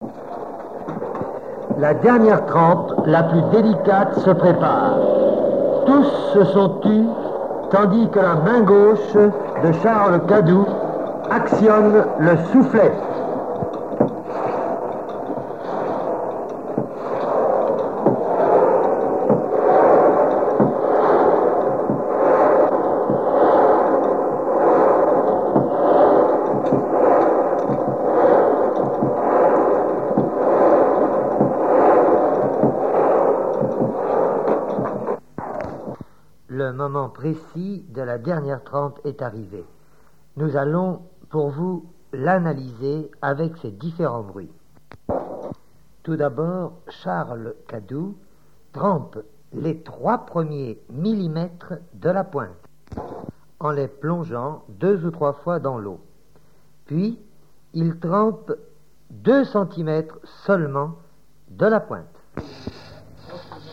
forgeron, forge
Île-d'Yeu (L')